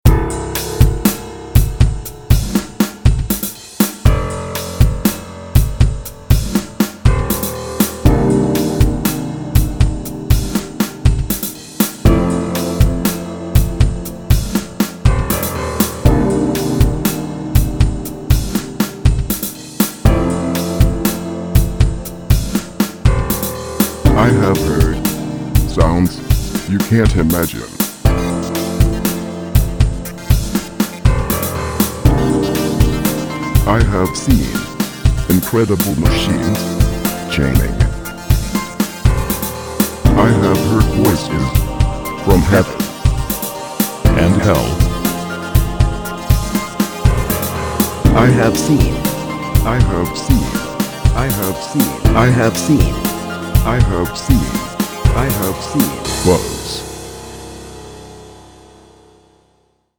Genre Laidback